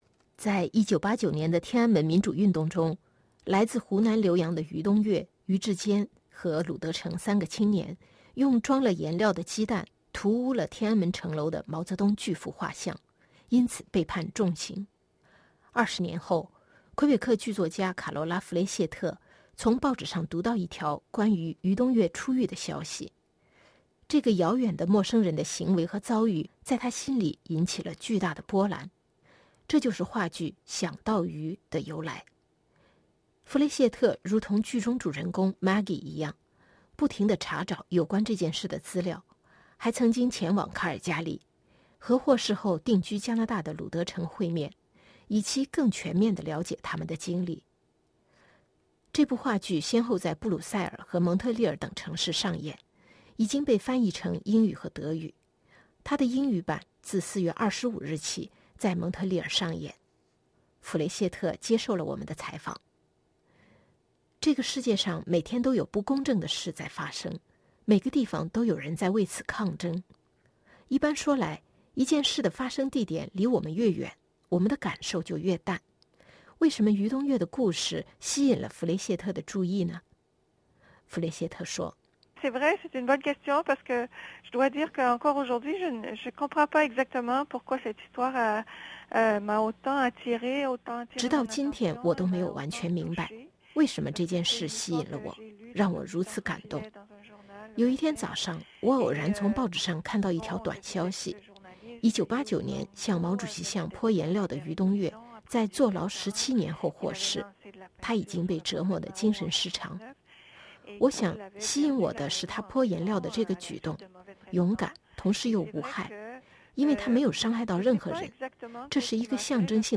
为什么写喻东岳：采访话剧《想到喻》作者弗雷谢特